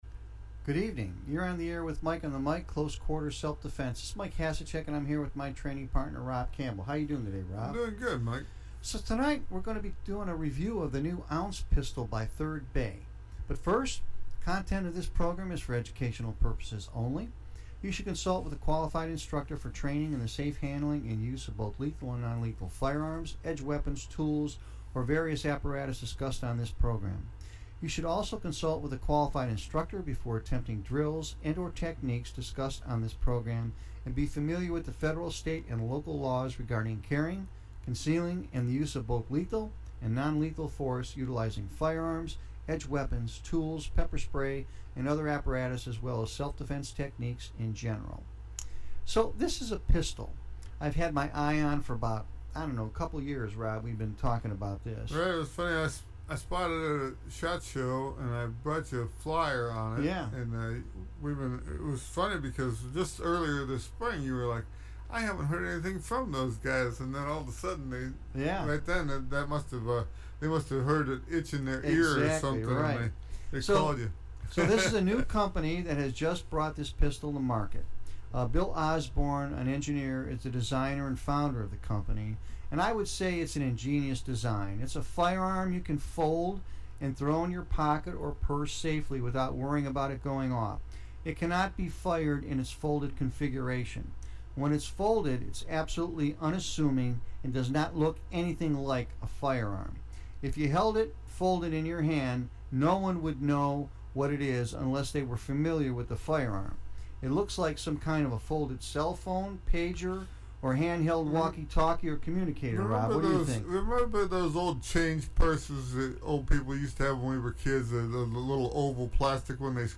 Audio review of Ounce®